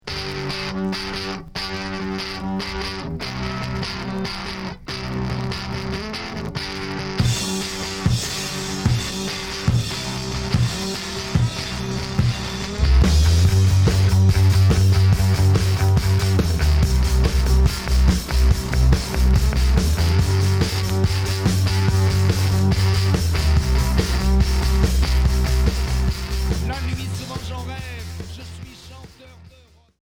Heavy rock Neuvième 45t retour à l'accueil